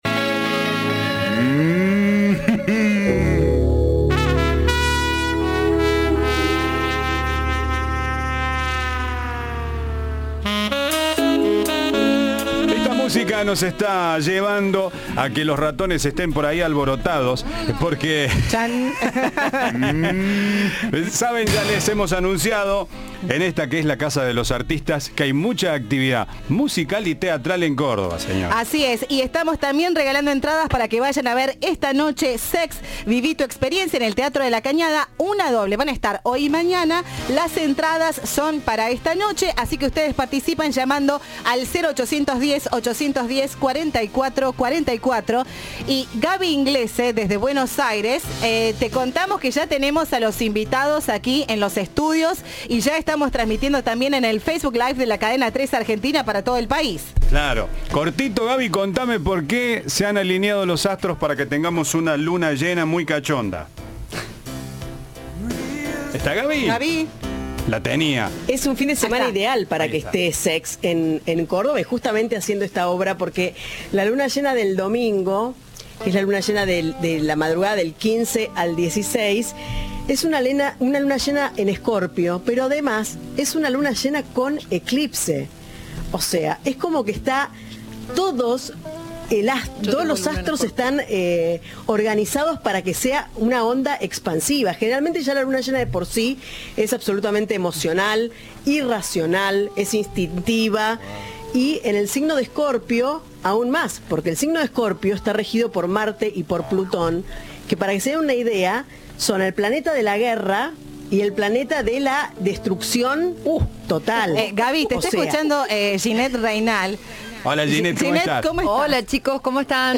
La obra que propone quitarse prejuicios y hablar sobre temas “tabúes” hará su presentación este viernes y sábado en el Teatro de la Cañada. En la previa al estreno, parte del elenco pasó por Cadena 3.